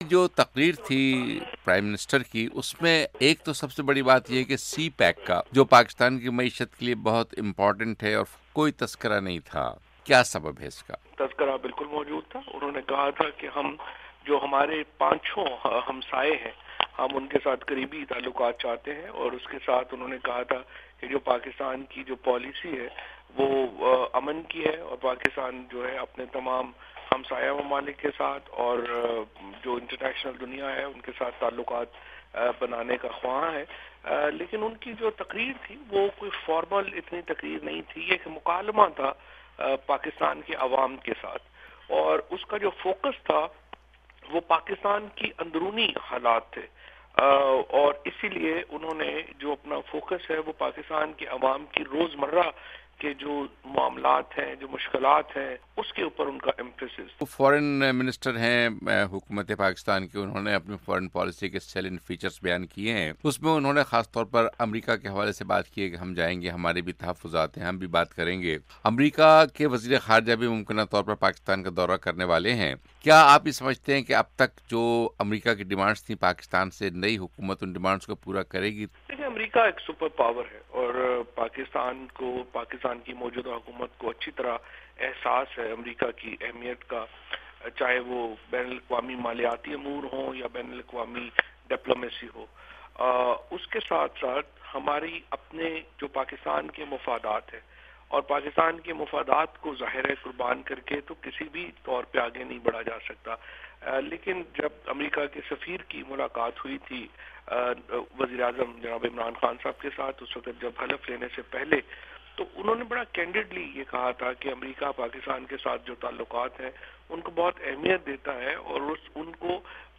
وزیر اطلاعات فواد چوہدری